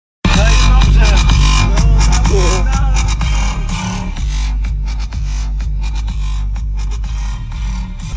2dychy blikiem puszcze jak mi ktoś znajdzie tą nute - Muzyka elektroniczna